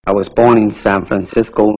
Born in San Francisco (Slowed Tempo)